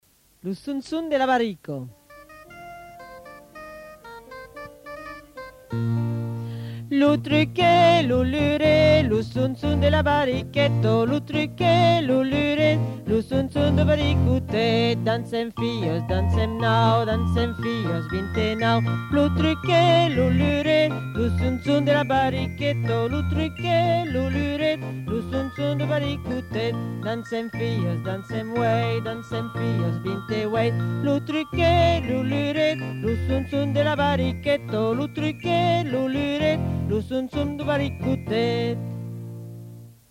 Des Comptines en Béarnais